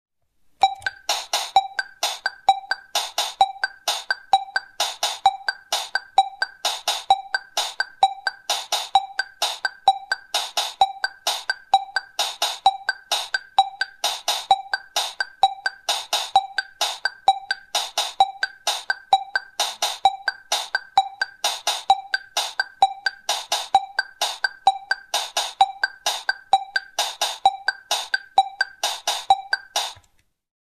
von Keyboard | MERZ Beruhigungsmusik